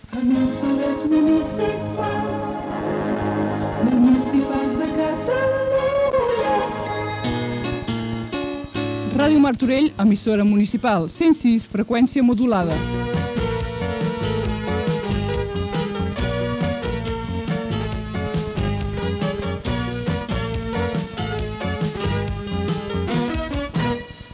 4988a72d105aa4a988e94e8d0c9cccc6d0f8227d.mp3 Títol Ràdio Martorell Emissora Ràdio Martorell Titularitat Pública municipal Descripció Indicatiu d'EMUC i identificació de l'emissora.